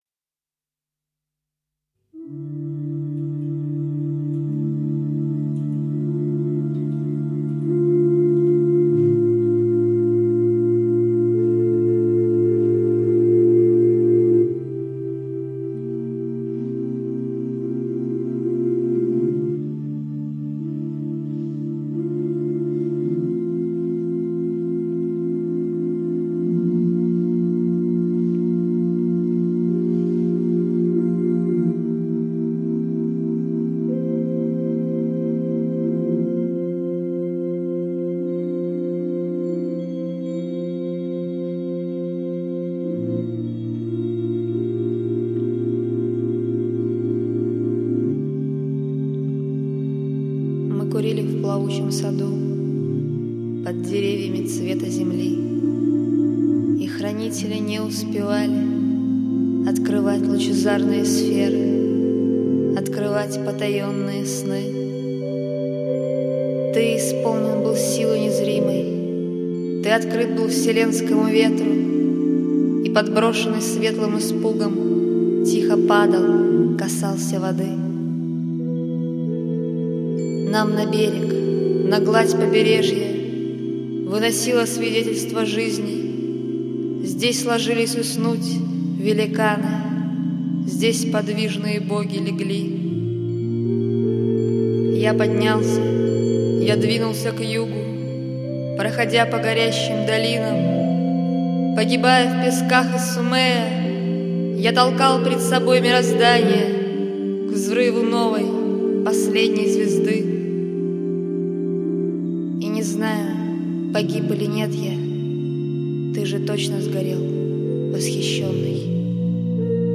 Альтернативная (2891)